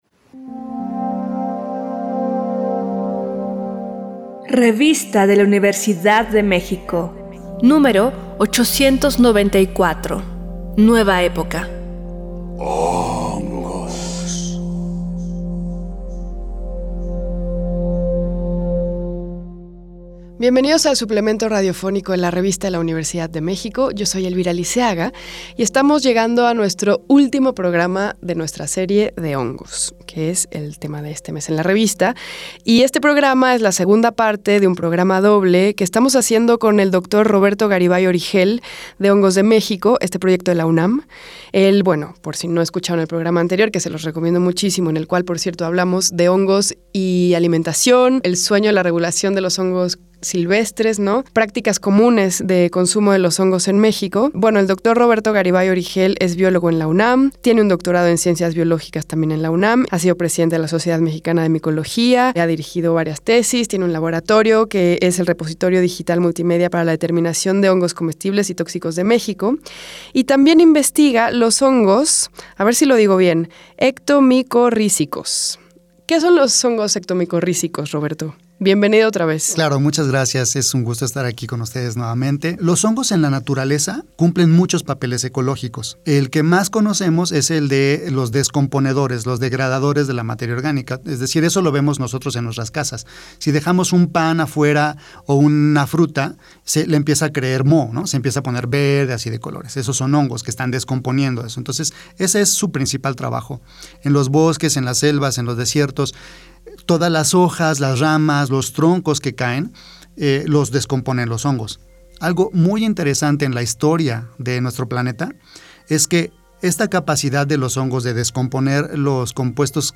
Este programa es una coproducción de la Revista de la Universidad de México y Radio UNAM. Fue transmitido el jueves 23 de marzo de 2023 por el 96.1 FM.